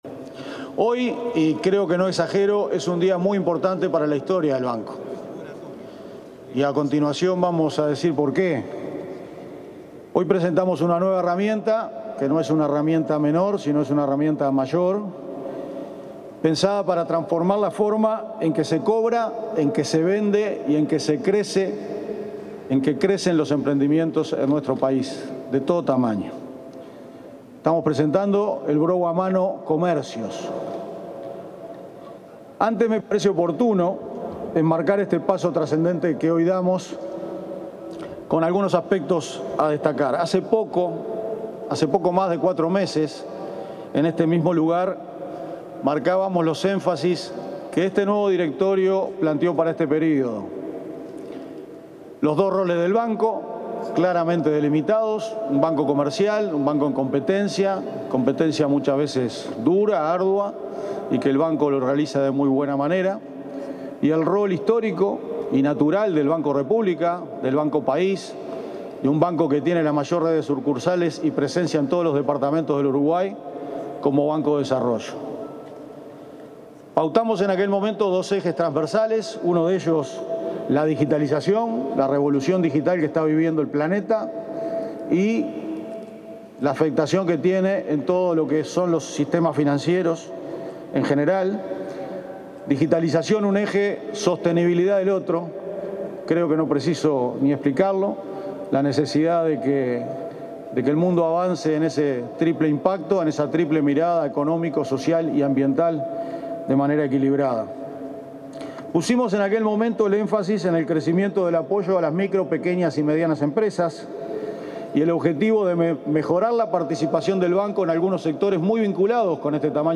El presidente del Banco de la República (BROU), Álvaro García, expuso en el lanzamiento de BROU a Mano Comercios.